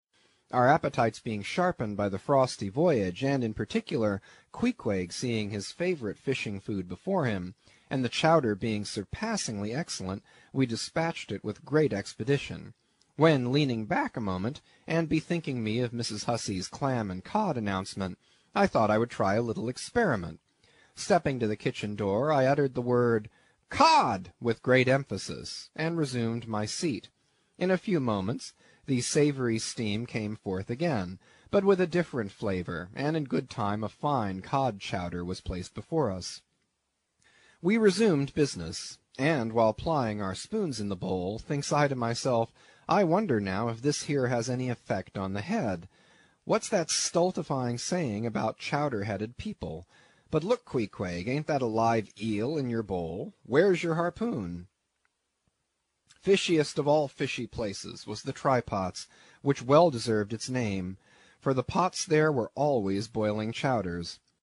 英语听书《白鲸记》第152期 听力文件下载—在线英语听力室